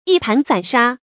一盤散沙 注音： ㄧ ㄆㄢˊ ㄙㄢˇ ㄕㄚ 讀音讀法： 意思解釋： 一盤黏合不到一起的沙子。